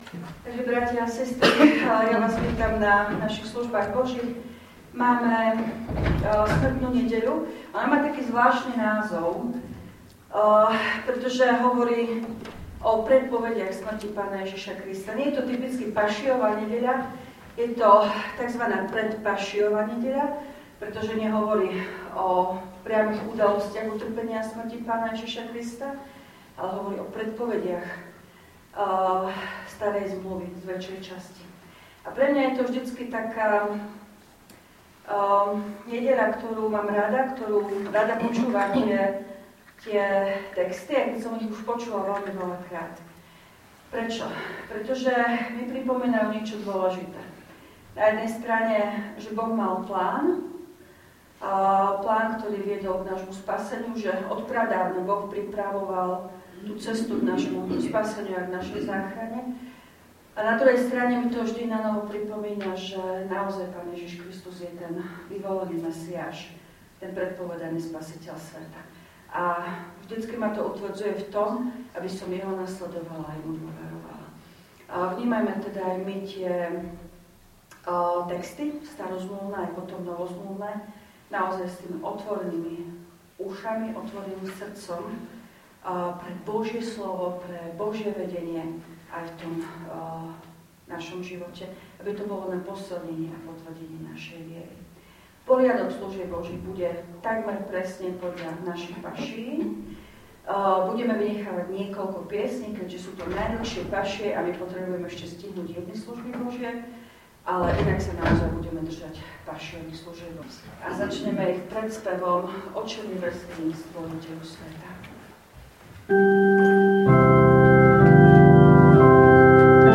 V nasledovnom článku si môžete vypočuť zvukový záznam zo služieb Božích – 5. nedeľa pôstna – Smrtná nedeľa.